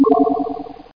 ballhit.mp3